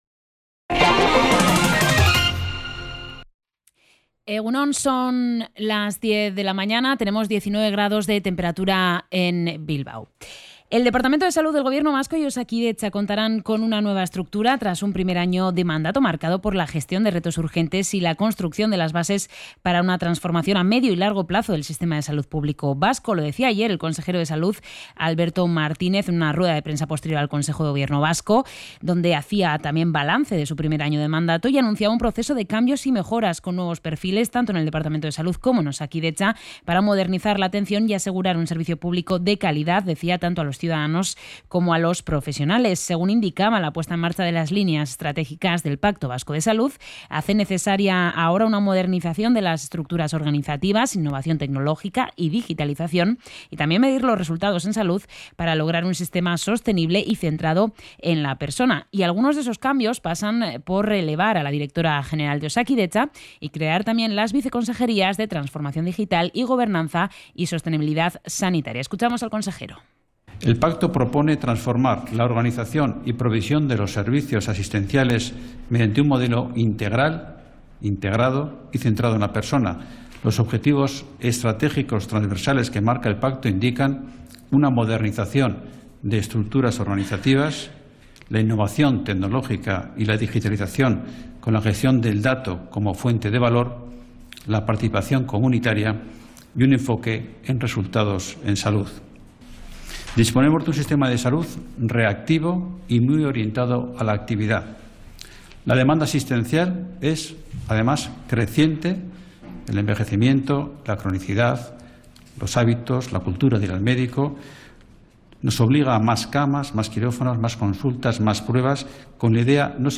Podcast Bizkaia